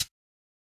• Quiet Pedal Hi-Hat Sample B Key 75.wav
Royality free hat sound sample tuned to the B note. Loudest frequency: 5661Hz
quiet-pedal-hi-hat-sample-b-key-75-WE9.wav